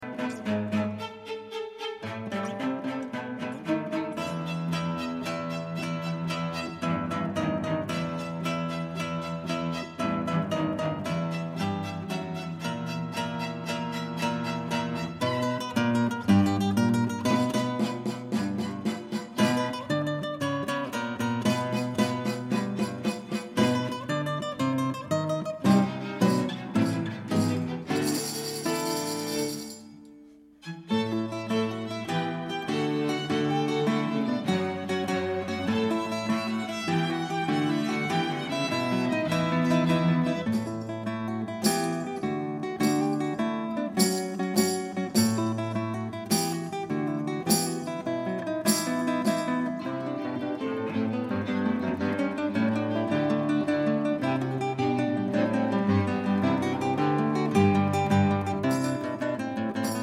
Audiobook
Read: Alfred Strejček